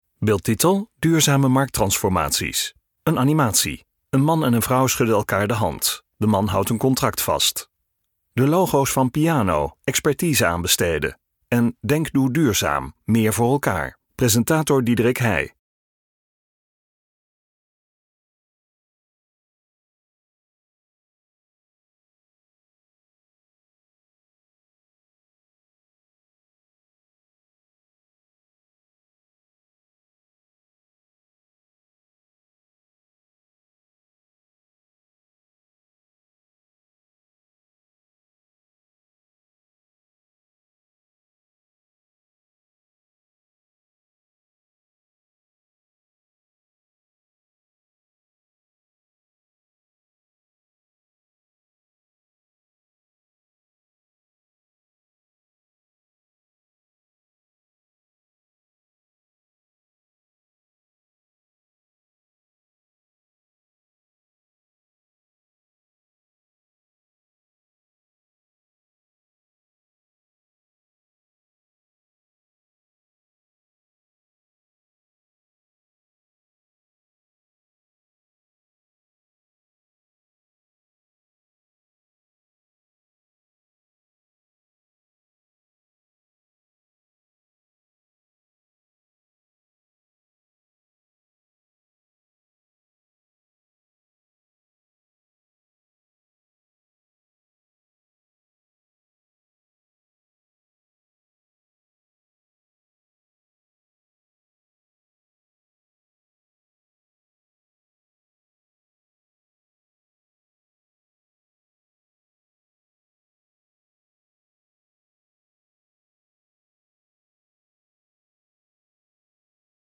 Op 9 maart organiseerde PIANOo dit webinar in samenwerking met Denk Doe Duurzaam, vooral gericht op opdrachtgevers bij de rijksoverheid.